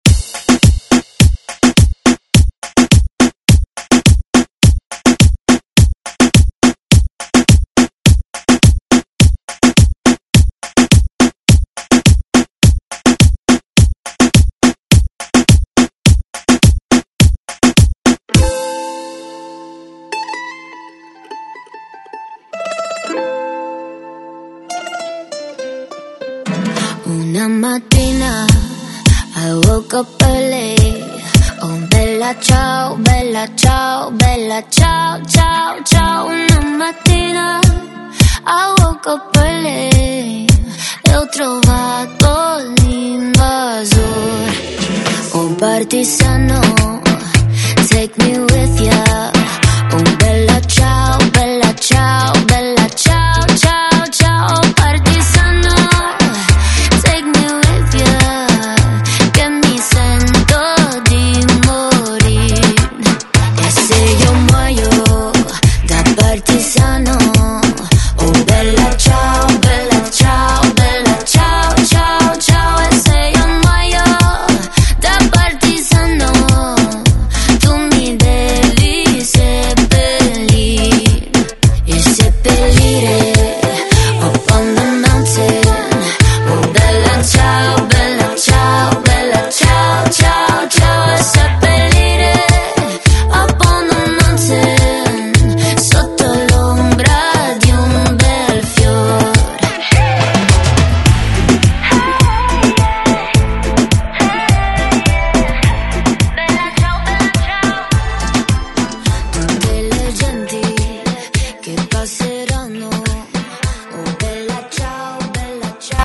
Genres: 80's , DANCE , RE-DRUM
Clean BPM: 131 Time